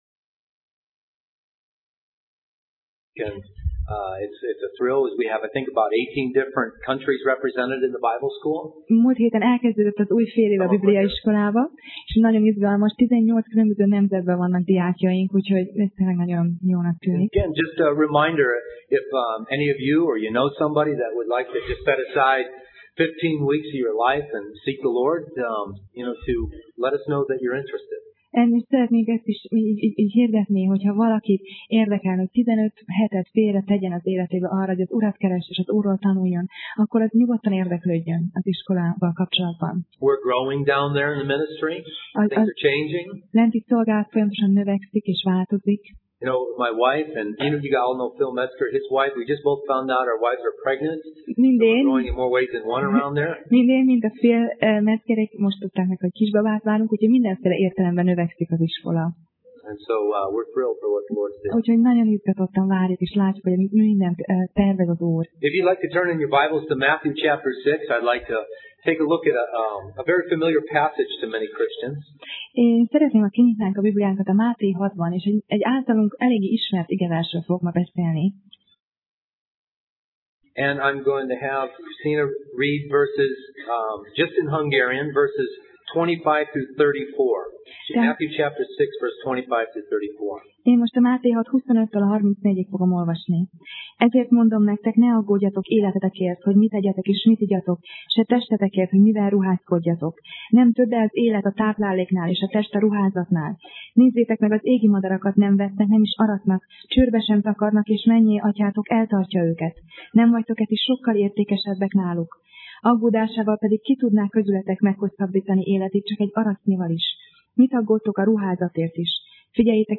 Passage: Máté (Matthew) 6:25-34 Alkalom: Vasárnap Reggel